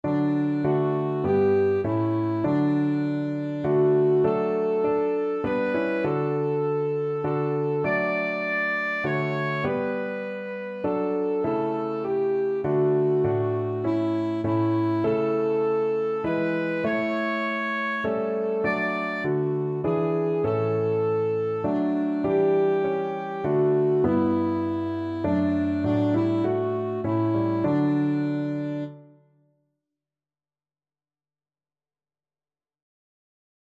Alto Saxophone version
3/4 (View more 3/4 Music)
Classical (View more Classical Saxophone Music)